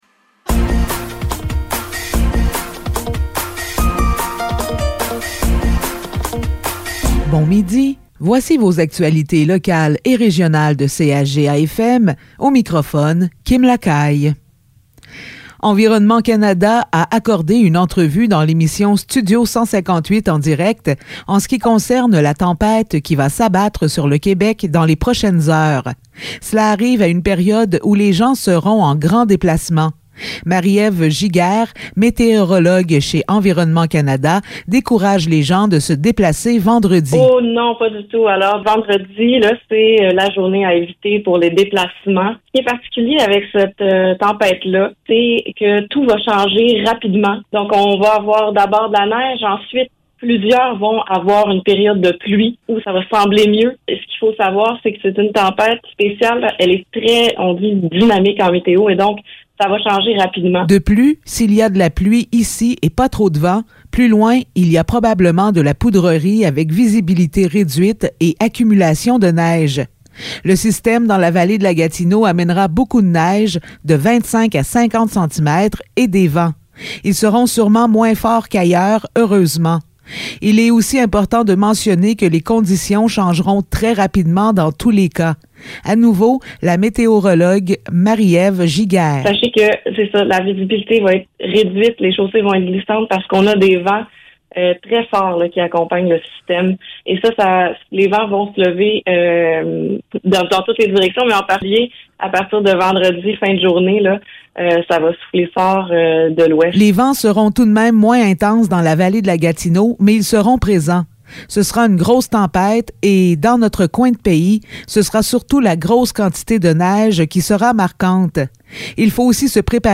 Nouvelles locales - 22 décembre 2022 - 12 h